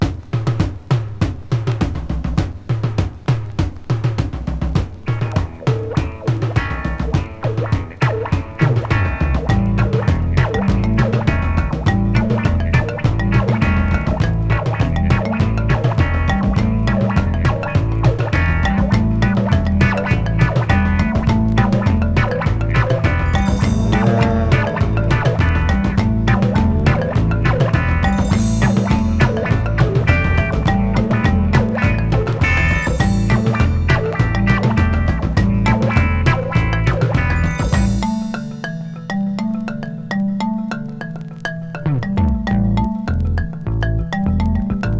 用途に応じて様々な音仕掛け。タンゴ有り、カントリー有り、秘境有り、ほんわか有り?!と、次から次へと聴き手を刺激。